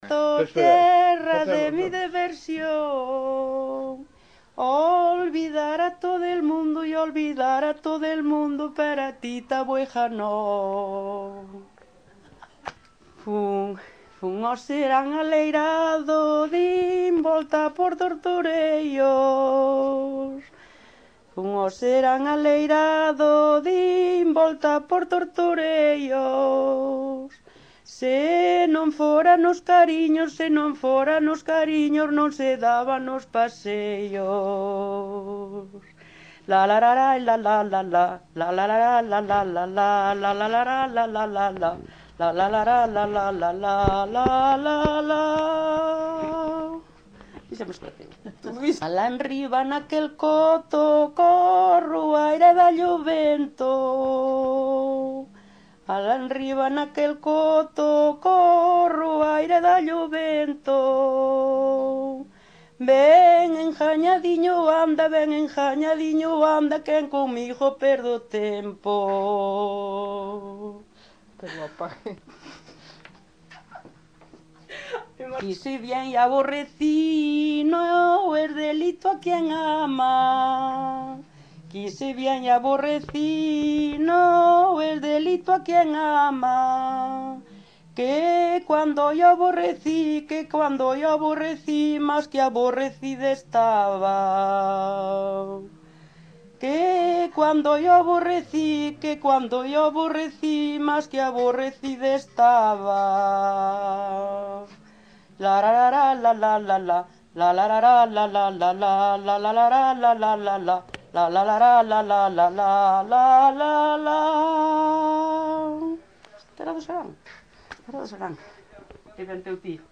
Coplas
Tipo de rexistro: Musical
Lugar de compilación: Neves, As - Taboexa (Santa María) - Carrasqueira, A
Soporte orixinal: Casete
Instrumentación: Voz
Instrumentos: Voz feminina